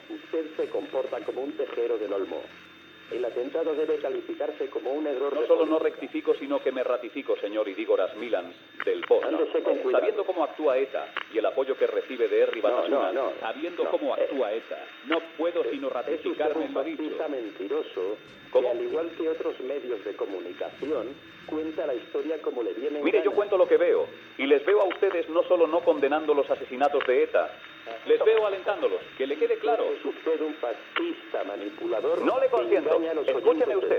Enfrontament dialèctic entre Luis del Olmo i el portaveu d'Herri Batasuna Jon Idígoras, després de l'assassinat del capità de Farmàcia Alberto Martín Barrios, el dia anterior (Havia estat segrestat per ETA el 5 d'octubre)
Info-entreteniment